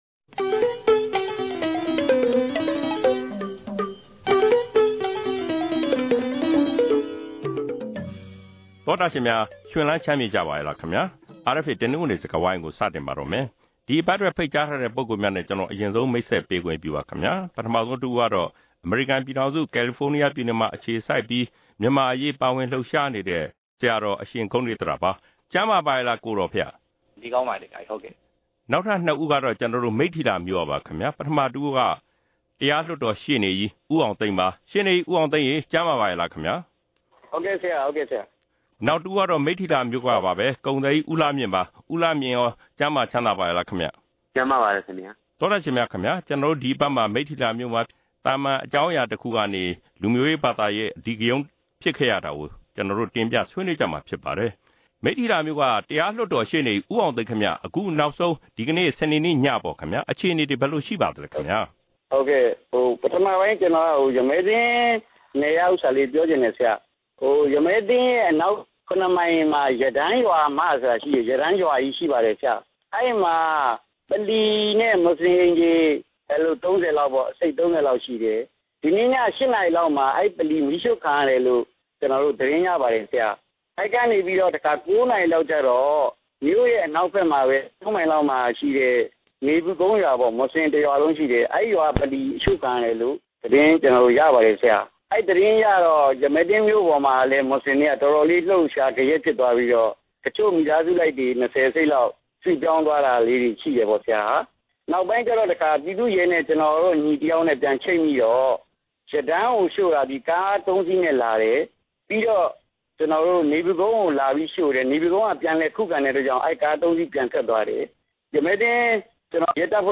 ဒီအပတ် တနင်္ဂနွေ စကားဝိုင်းမှာ မိတ္ထီလာအရေးအခင်း ဖြစ်ပွားခဲ့ပုံနဲ့ နောက်ဆုံးအခြေအနေတွေကို နားဆင်ရမှာဖြစ်ပါတယ်။